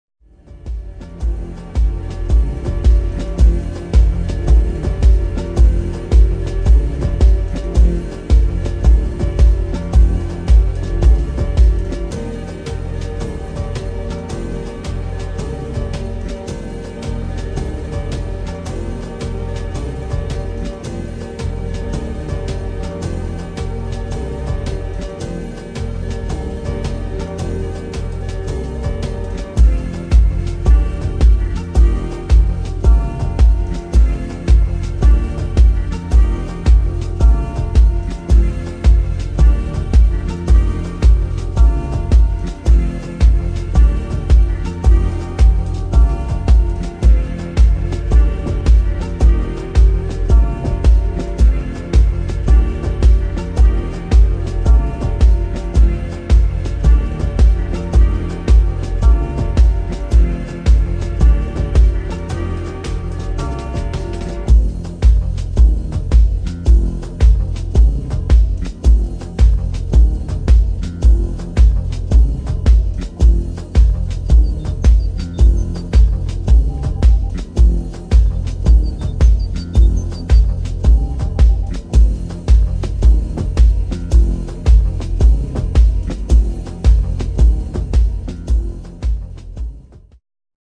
[ TECHNO | AMBIENT ]